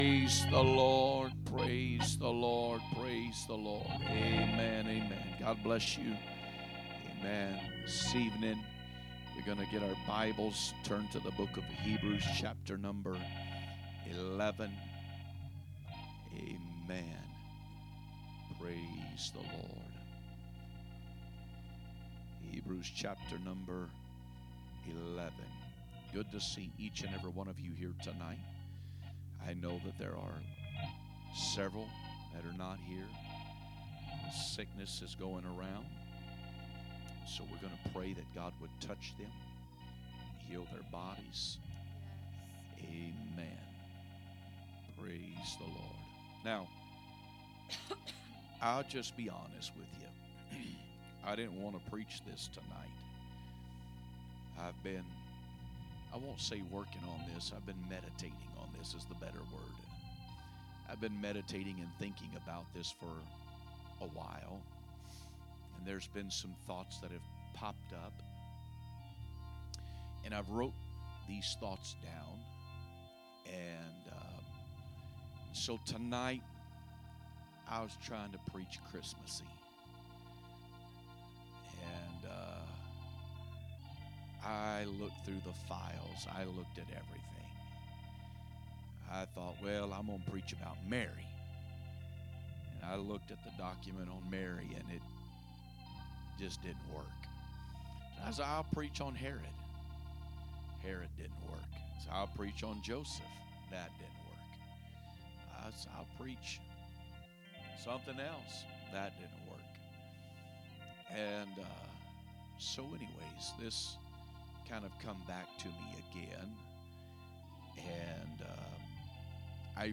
A message from the series "2025 Preaching." Wednesday Service